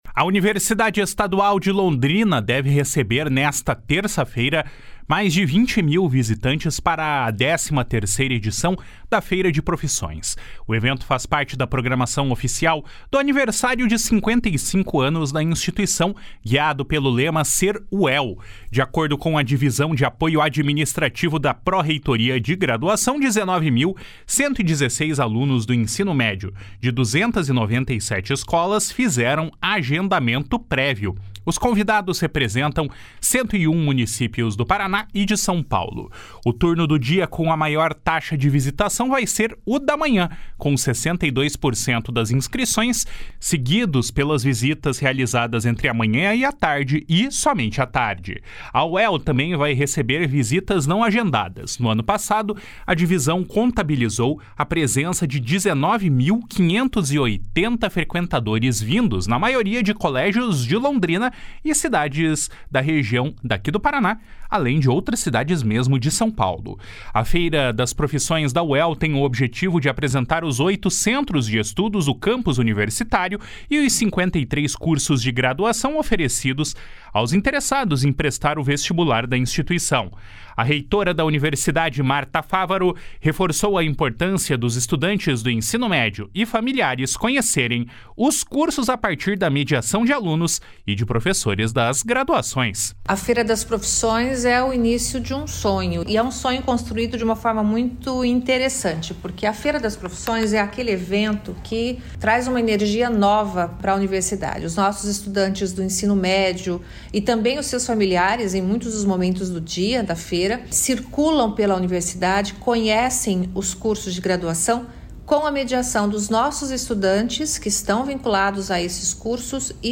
A reitora da universidade, Marta Favaro, reforçou a importância de estudantes do Ensino Médio e familiares conhecerem os cursos a partir da mediação de alunos e professores das graduações.